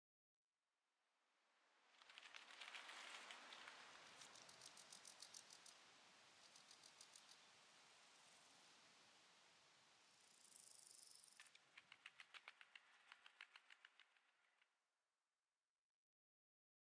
insect1.ogg